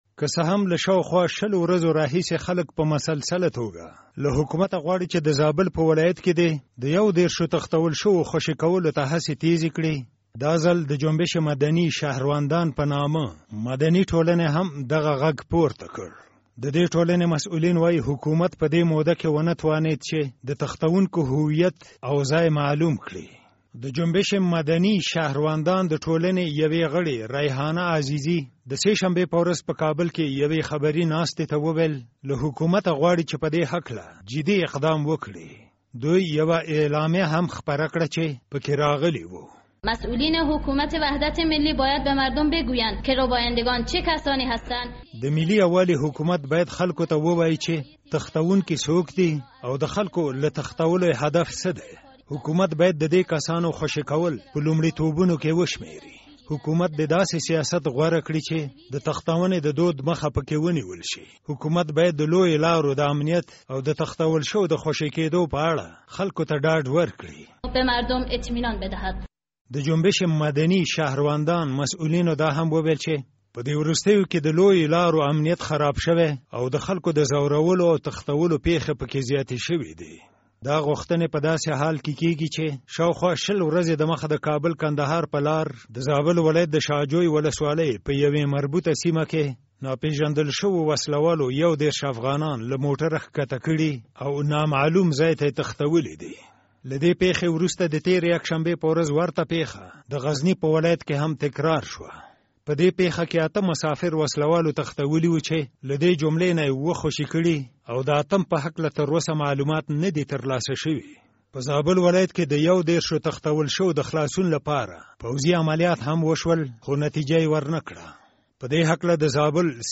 راپور